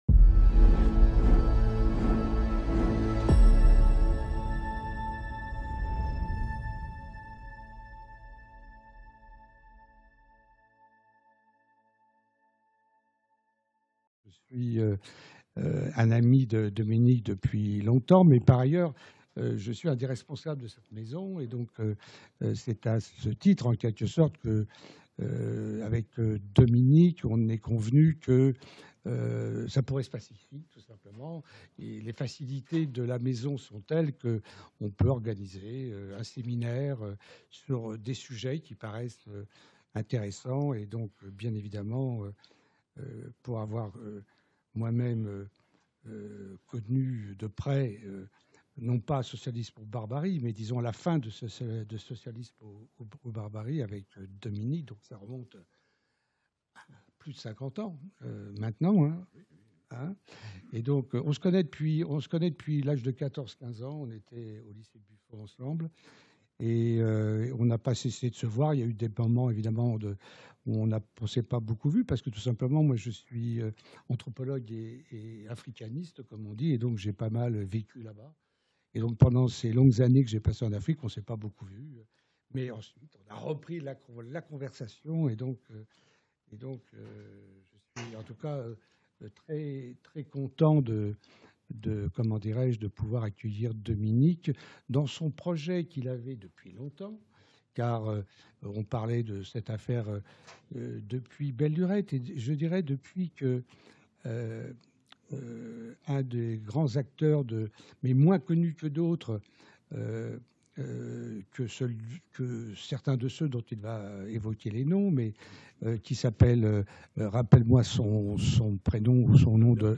Première séance du séminaire